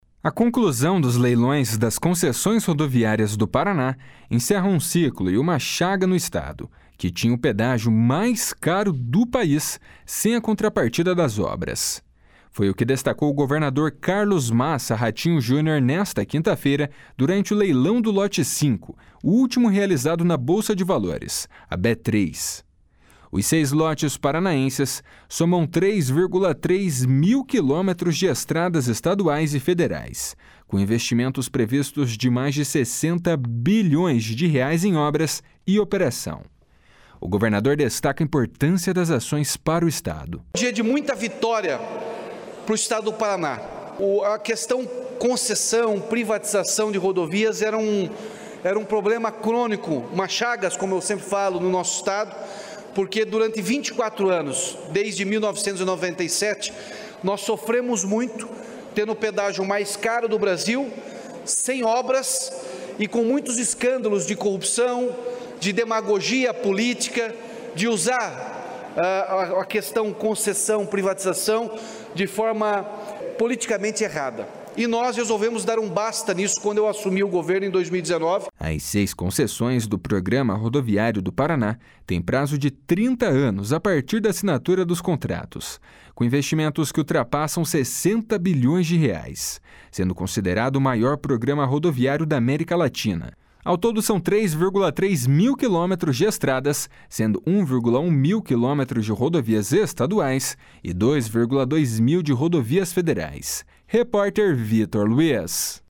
Foi o que destacou o governador Carlos Massa Ratinho Junior nesta quinta-feira, durante o leilão do lote 5, o último realizado na Bolsa de Valores, B3.
// SONORA RATINHO JUNIOR //